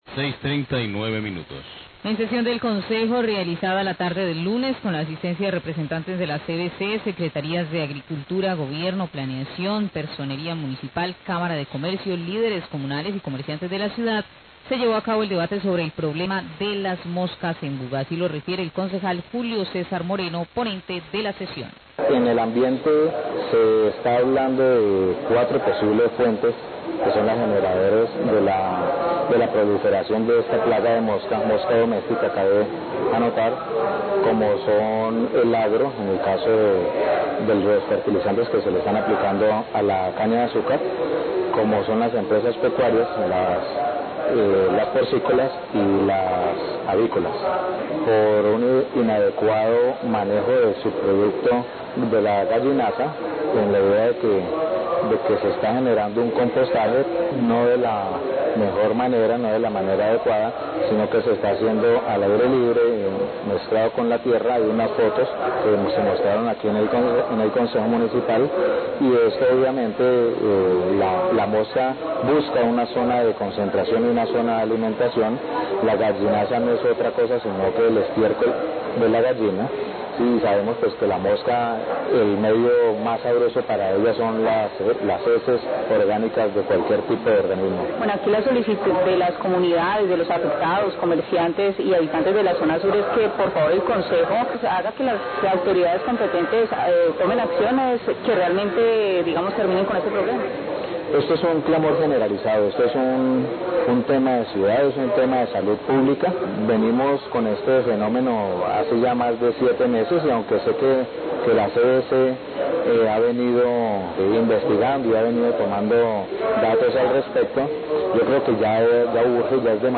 Radio
La comunidad exige soluciones inmediatas a este factor de salud pública. Concejal Julio César Moreno brinda un balance de la reunión, indica que se plantearon cuatro posibles fuentes que son las generadoras de la proliferación de esta plaga de mosca doméstica, cómo lo son el agro debido a los fertilizantes que se aplica a la caña de azúcar, las empresas pecuarias, piscícolas y avícolas.